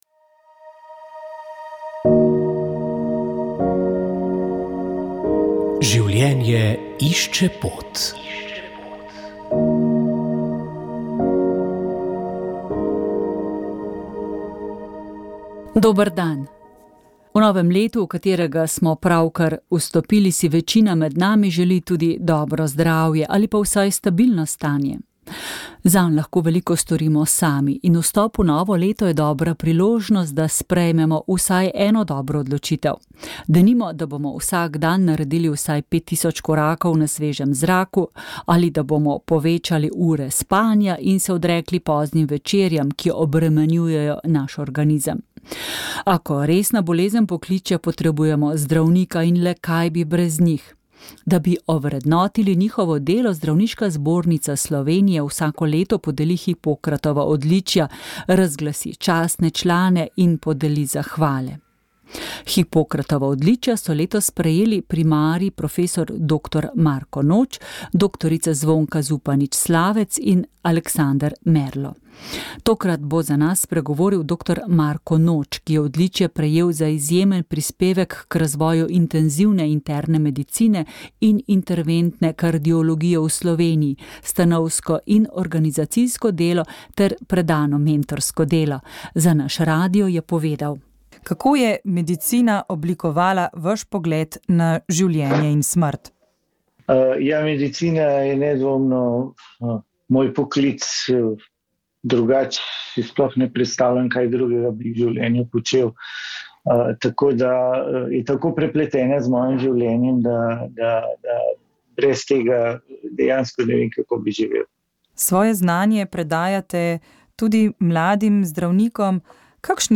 Priporočamo | Aktualno Komentar tedna VEČ ...